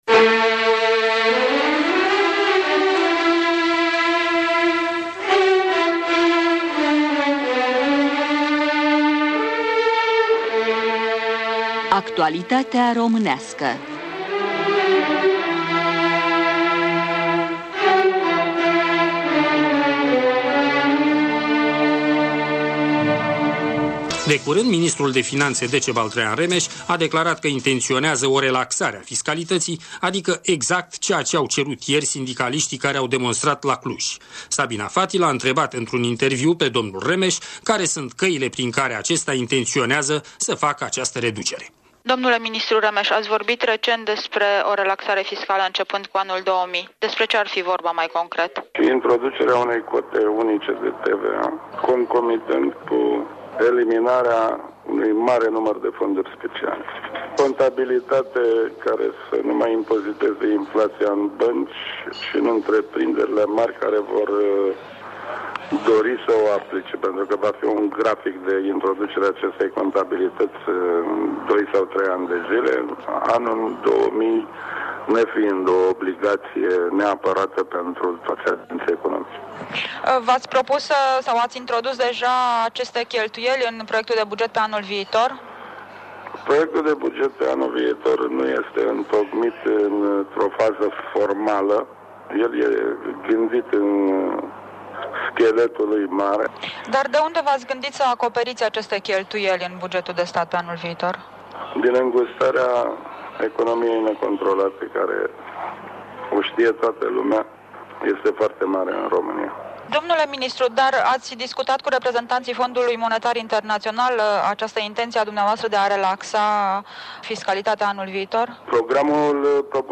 Interviul cu ministrul de finanțe de la București